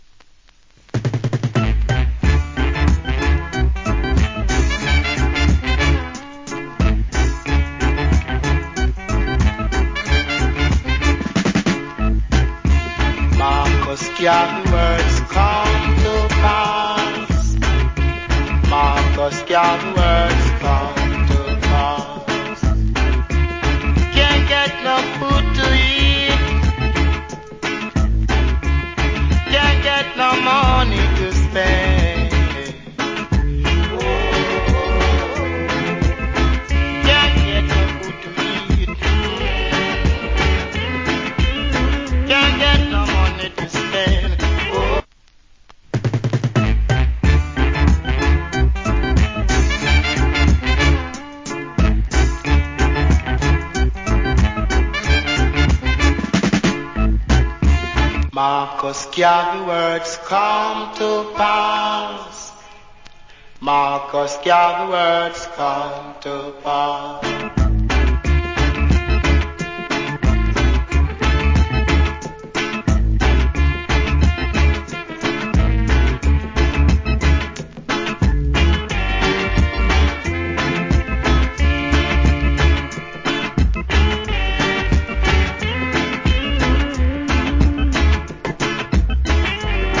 Foundation Roots.